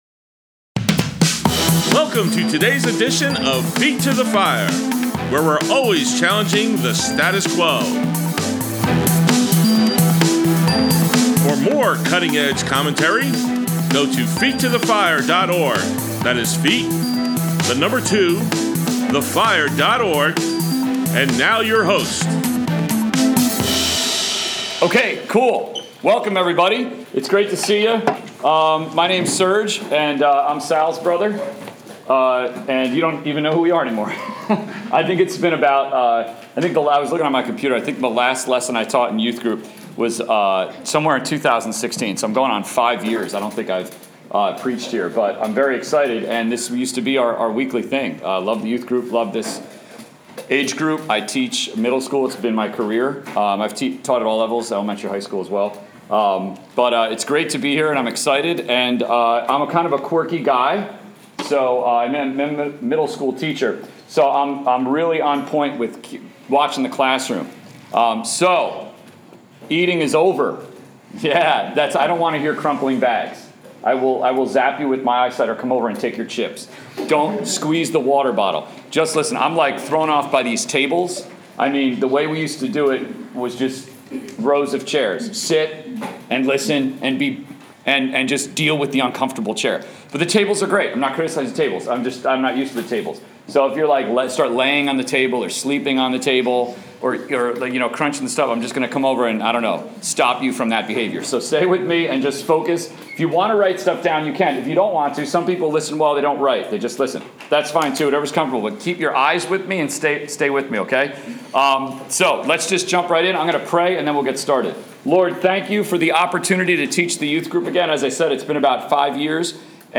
Saturday Sermons: Resurrection Power at Work in Us, Eph 1:19-21
Grace Bible Church Youth Group